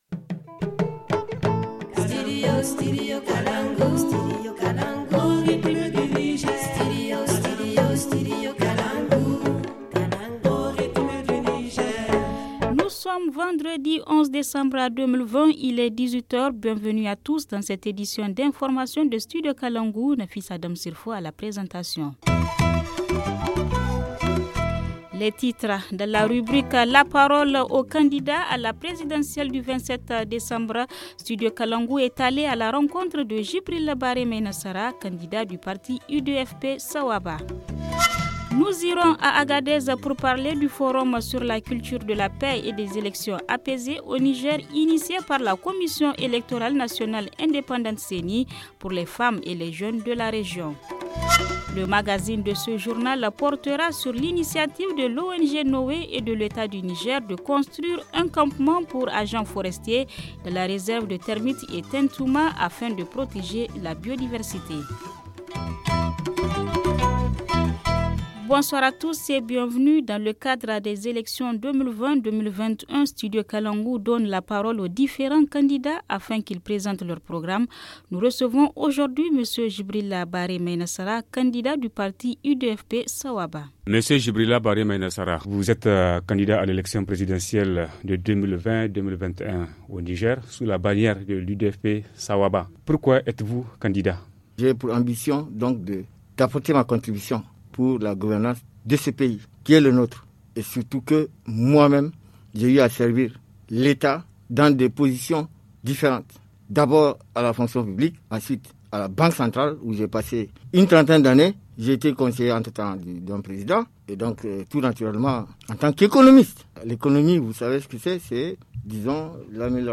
Le journal du 11 décembre 2020 - Studio Kalangou - Au rythme du Niger